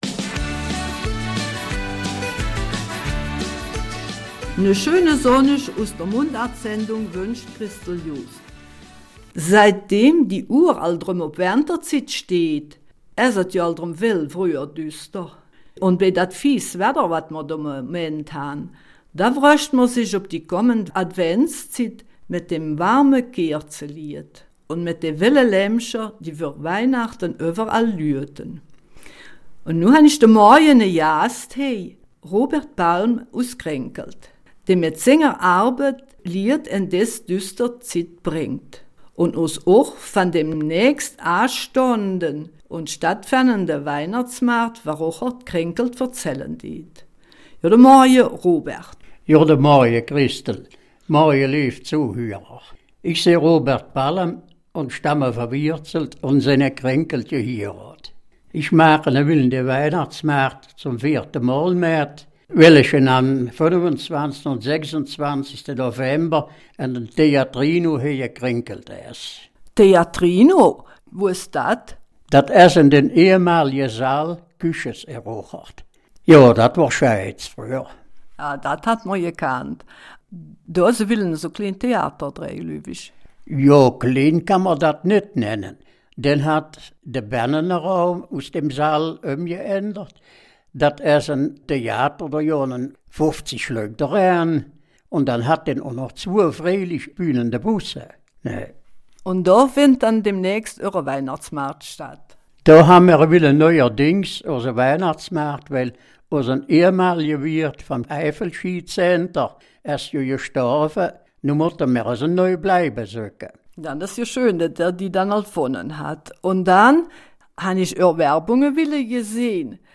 Eifeler Mundart: Weihnachtsmarkt von Krinkelt-Rocherath